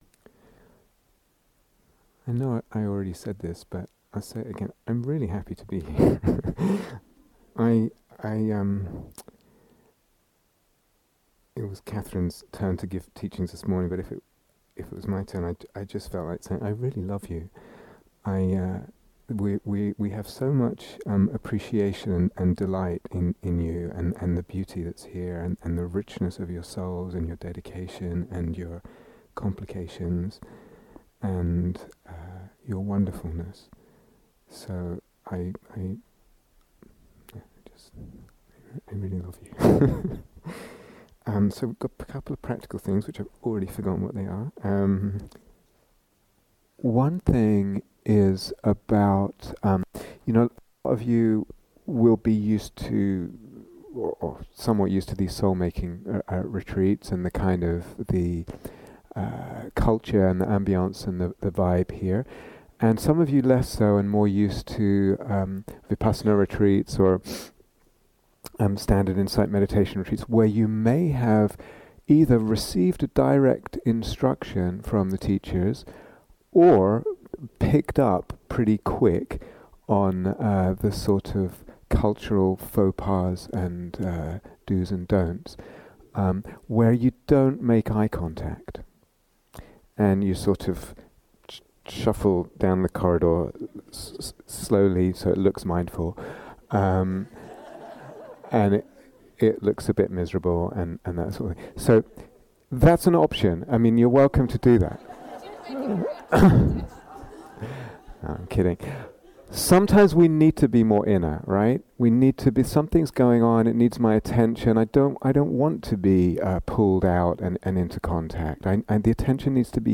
The Imaginal-Energy Body (Q & A)